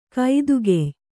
♪ kaidugey